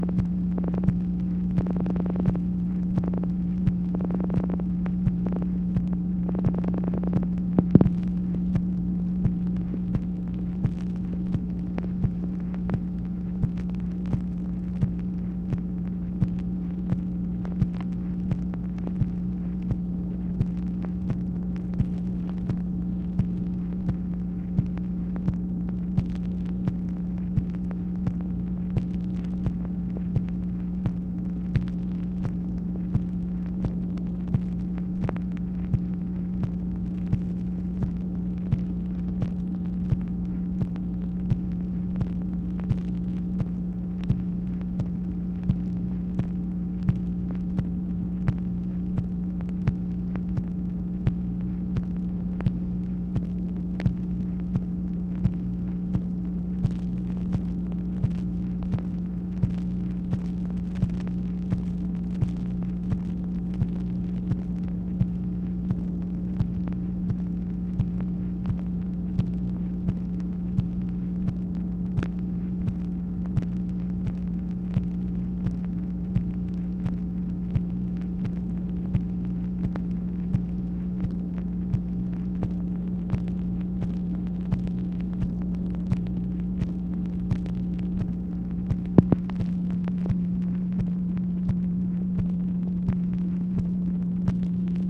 MACHINE NOISE, January 14, 1966
Secret White House Tapes | Lyndon B. Johnson Presidency